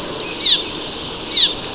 Golondrina de Peñasco,
Cliff Swallow
Petrochelidon pyrrhonota